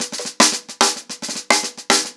TheQuest-110BPM.27.wav